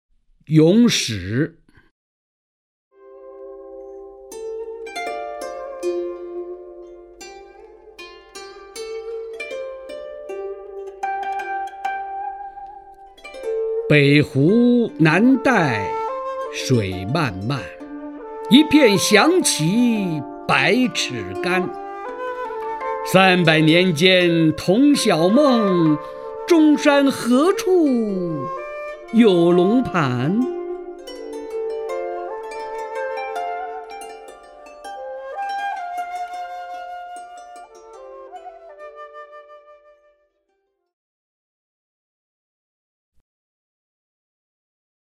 曹灿朗诵：《咏史》(（唐）李商隐) （唐）李商隐 名家朗诵欣赏曹灿 语文PLUS